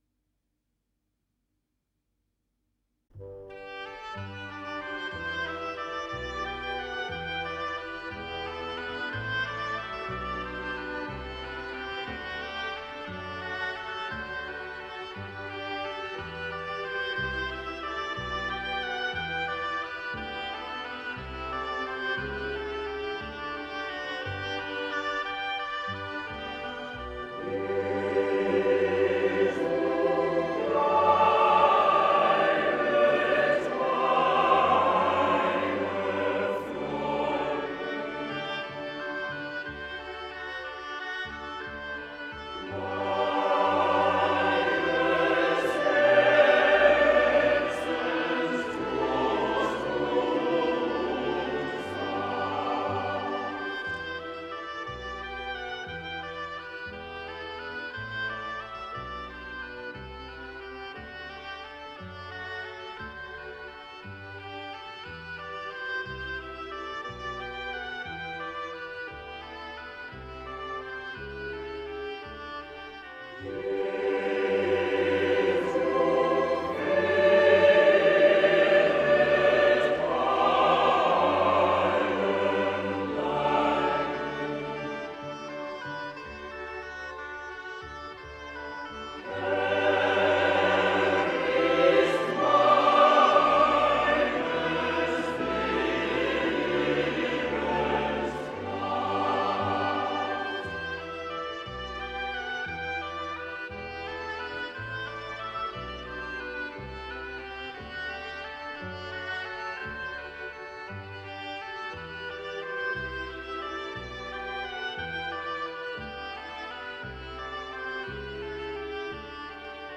Главная / Песни для детей / Классическая музыка